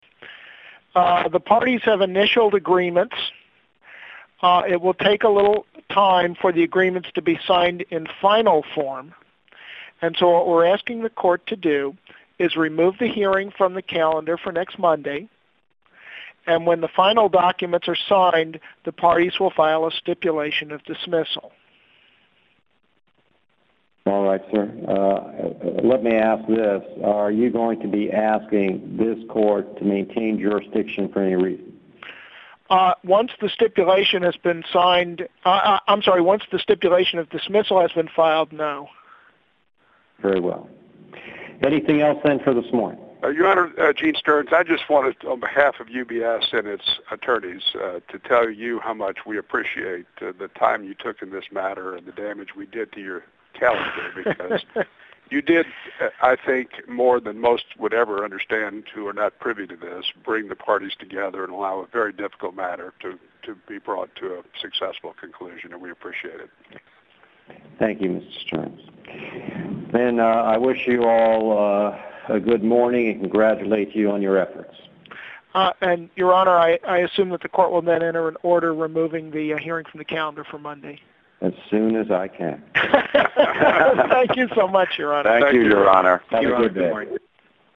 Conference call between the parties involved in the case